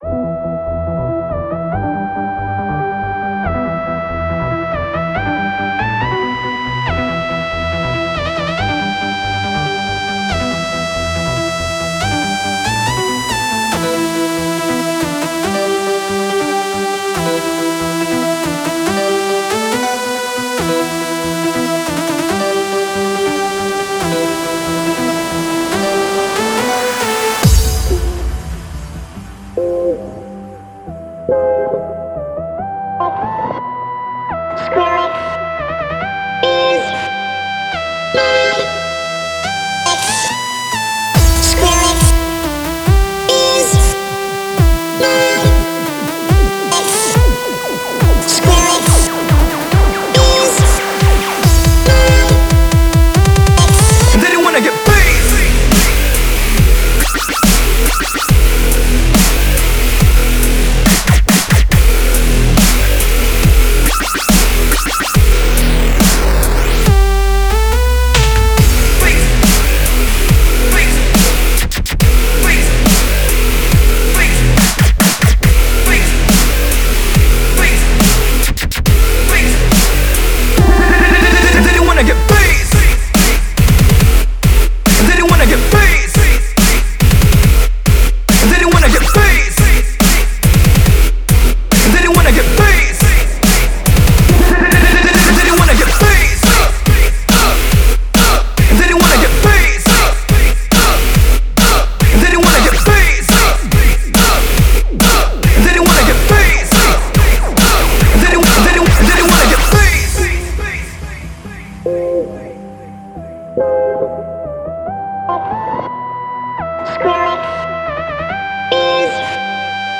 DUB STEP--> [6]